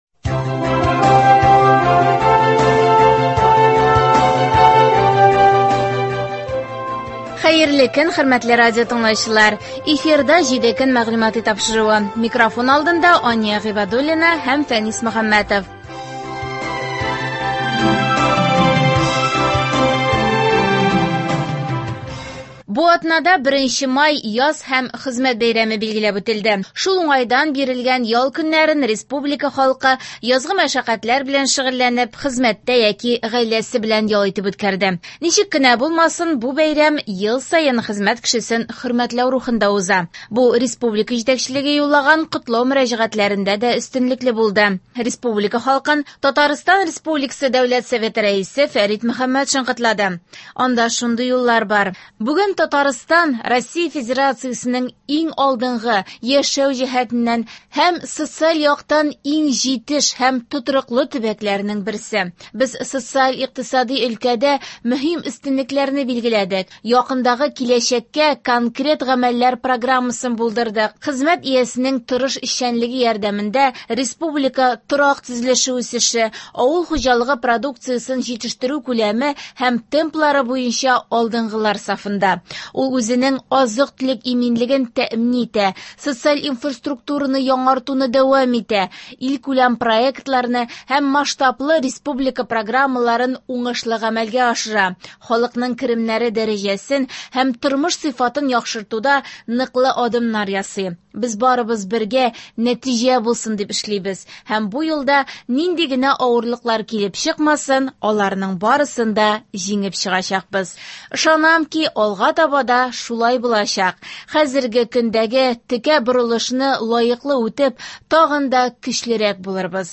Атналык күзәтү. Бу атнада 1 май — Яз һәм Хезмәт көне билгеләп үтелде.